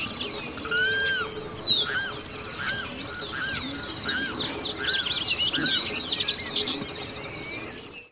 Carão (Aramus guarauna)
Nome em Inglês: Limpkin
Localidade ou área protegida: Reserva Natural del Pilar
Condição: Selvagem
Certeza: Gravado Vocal